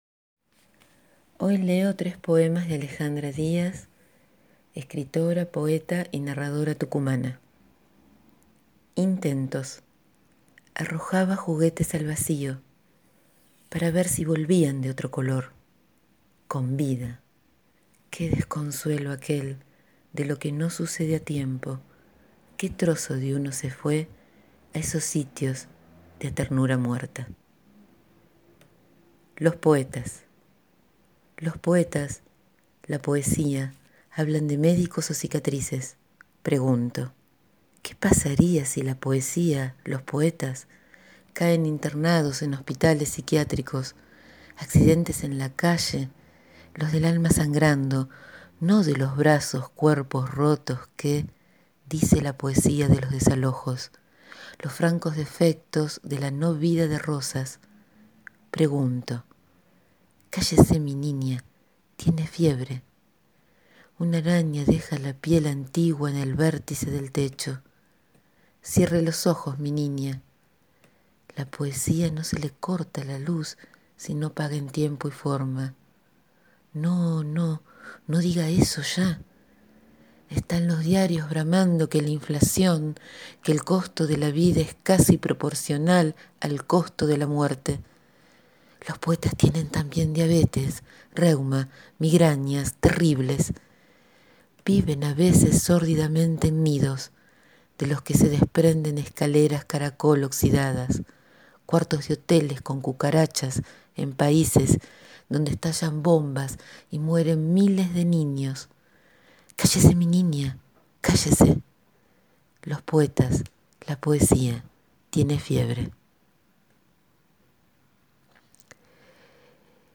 Selección de poemas
Hoy leo poemas